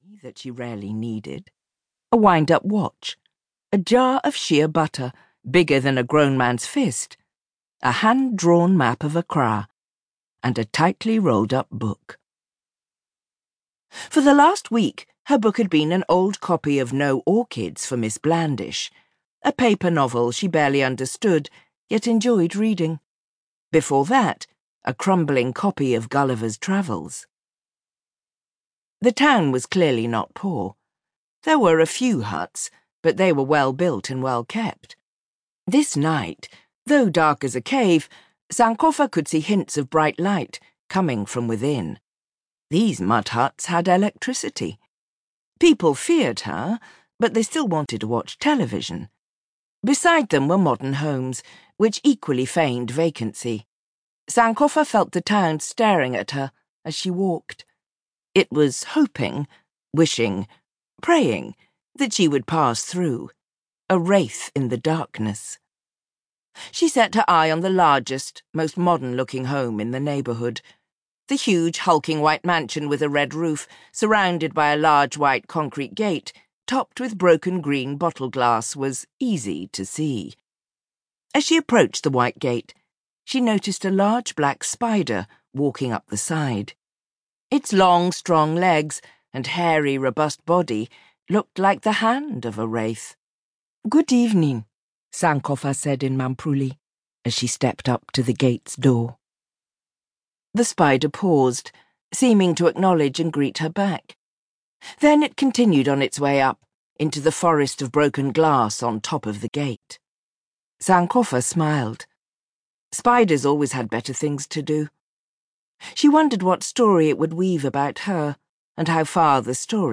• Audiobook
"Narrator Adjoa Andoh captivates listeners with a stunning new sci-fi novella set in a near-future Ghana. Andoh is perfectly in tune with Okorafor's compelling story, smoothly switching between her British accent as the narrator and the intonations of the vibrant characters she brings to life." -- AudioFile Magazine